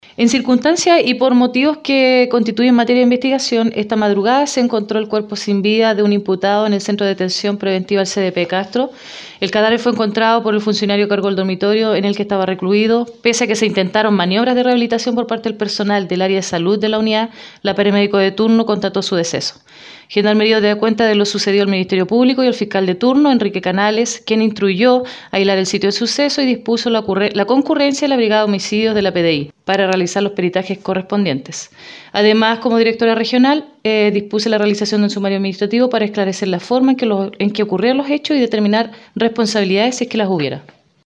De esta manera lo confirmó Gendarmería por intermedio de la directora regional Los Lagos, Teniente Coronel Angélica Briones Castillo.